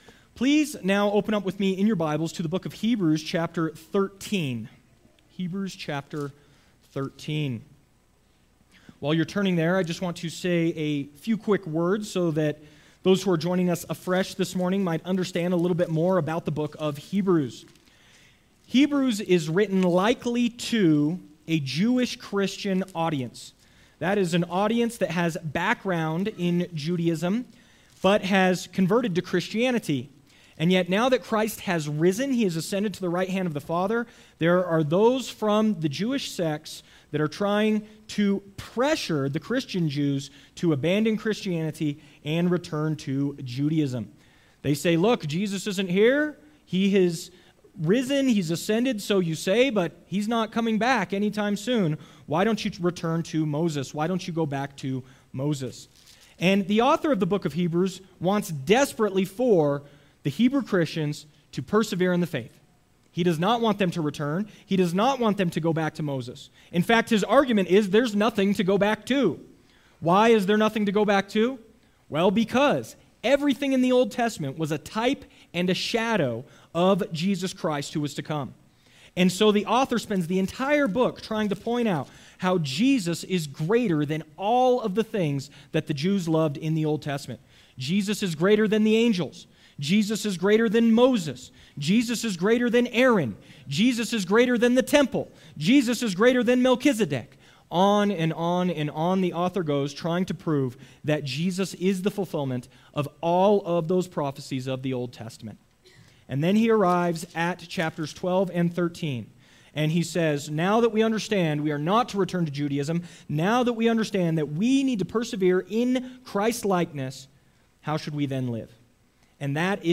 followed by an open Q&A.